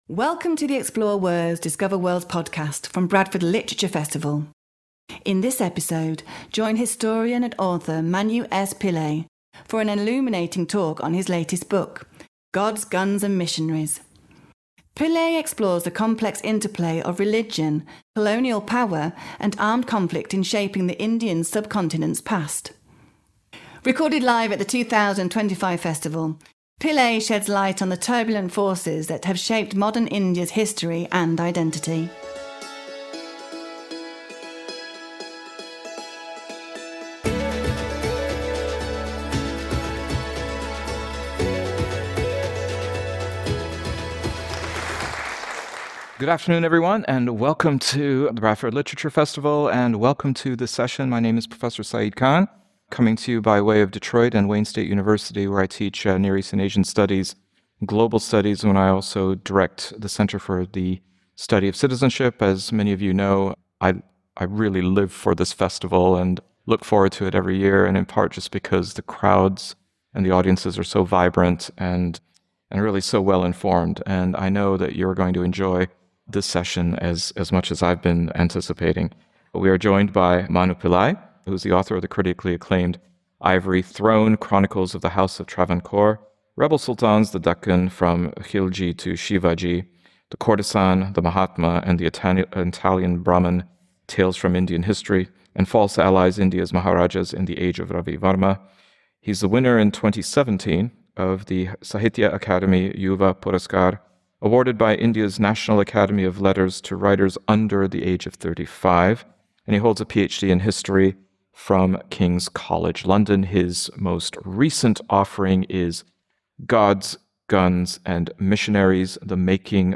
for an illuminating talk on his latest book